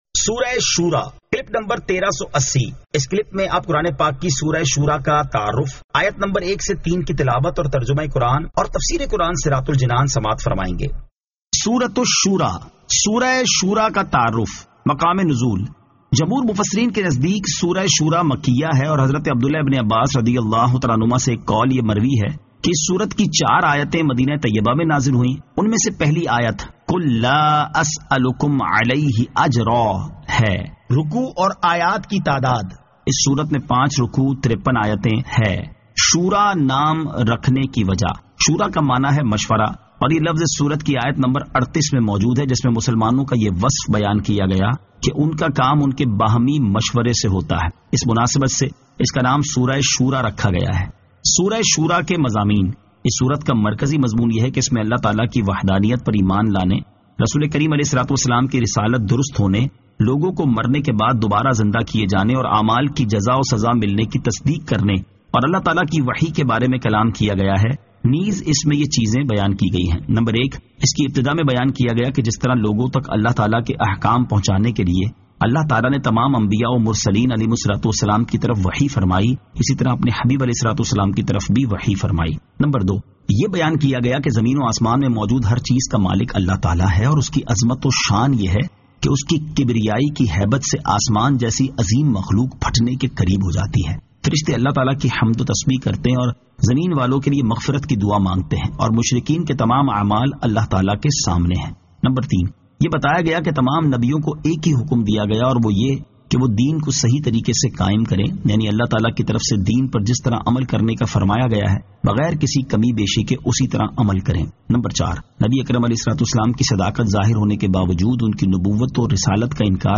Surah Ash-Shuraa 01 To 03 Tilawat , Tarjama , Tafseer